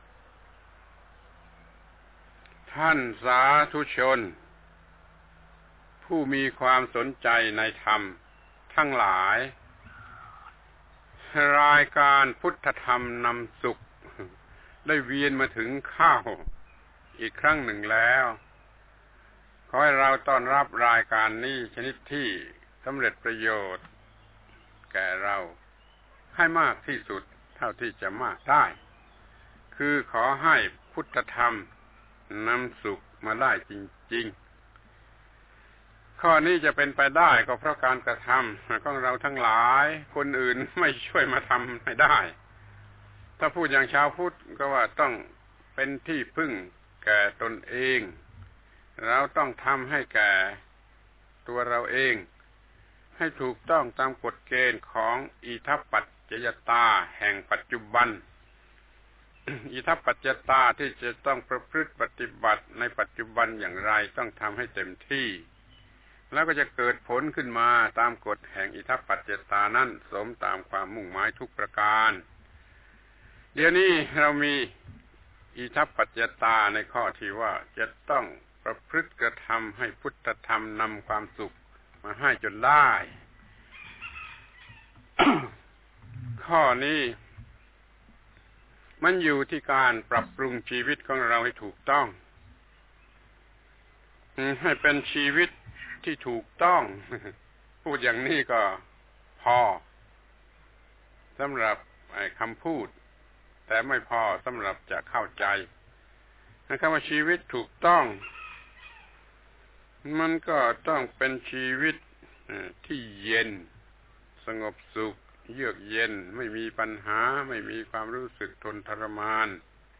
พระธรรมโกศาจารย์ (พุทธทาสภิกขุ) - ปาฐกถาธรรมทางโทรทัศน์สุราษฯและหาดใหญ่ รายการพุทธธรรมนำสุข ครั้ง ๔๗ ต้องทำให้ถูกต้องกฎอิทัปปัจจยตาแห่งปัจจุบัน